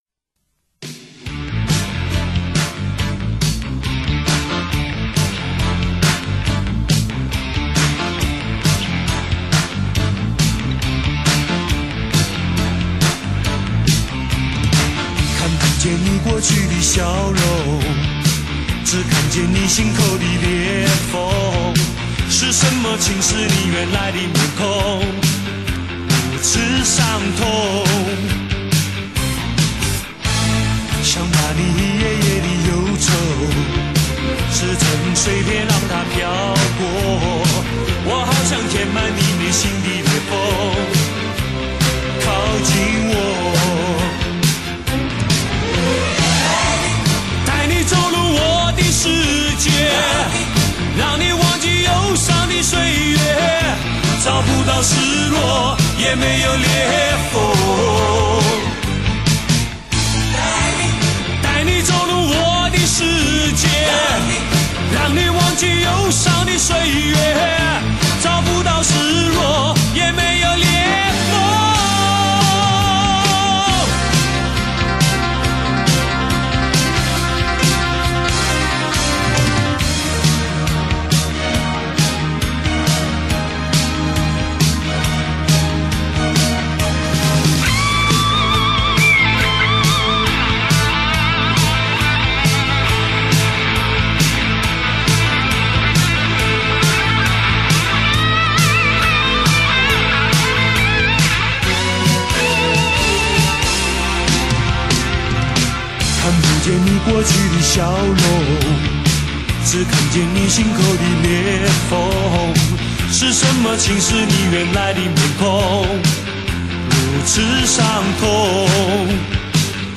台湾摇滚第一人，他的歌很朴实、真诚，旋律朗朗上口。